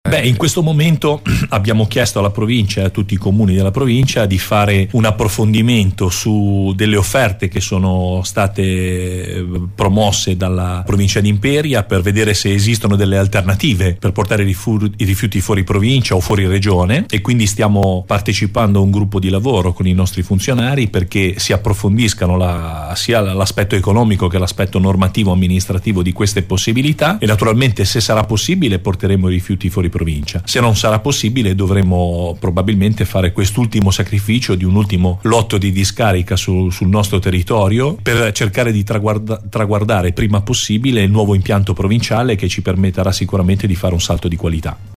Sono stati molti i temi affrontati oggi dal sindaco di Taggia, Vincenzo Genduso, ospite negli studi di Radio Onda Ligure 101 per la trasmissione 'Sindaco In Onda'.